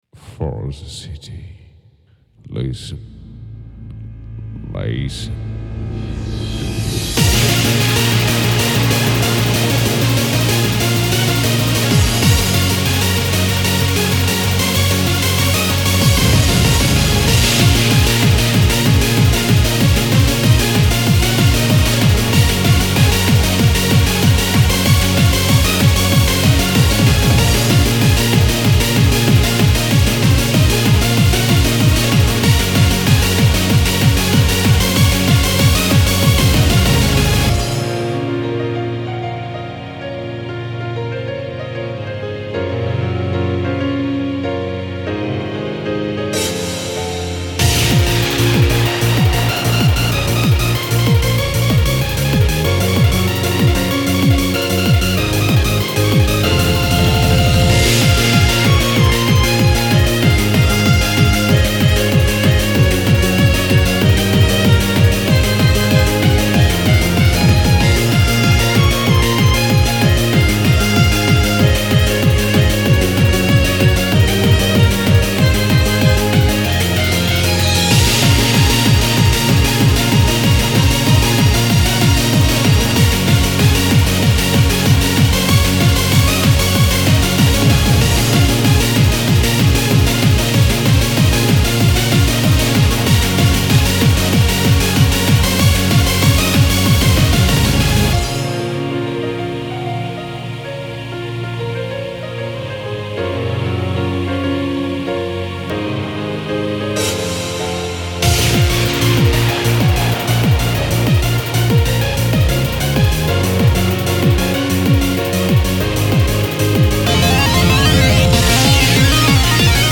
HARD RENAISSANCE